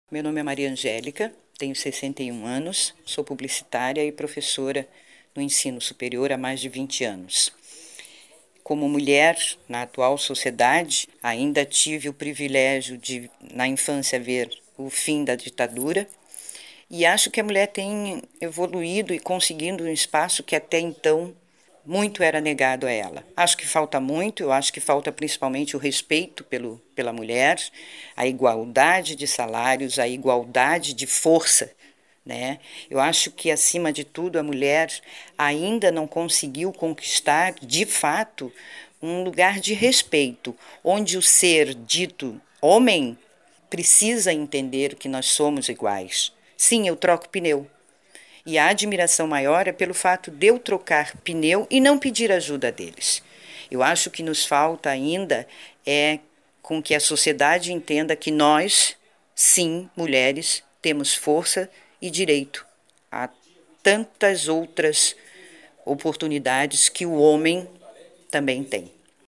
Ouvimos mulheres de várias idades, que viveram momentos diferentes dessa história, para saber o que ainda falta para o mundo se tornar um lugar bom para elas.